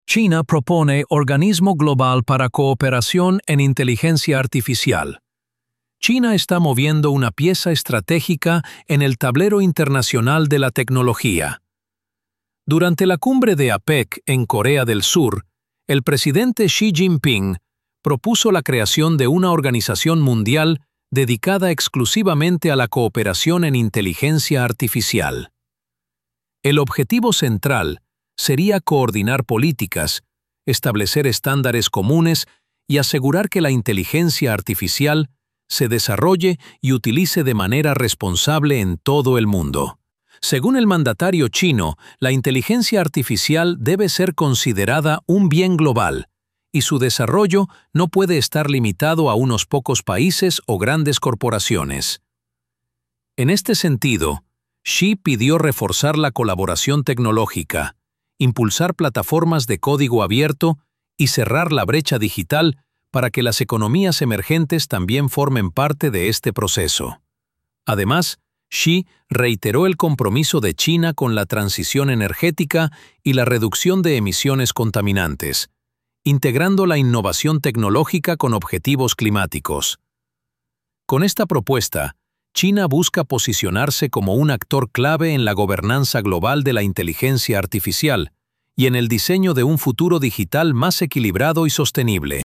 Narración diplomática · MP3 · ~70–90 segundos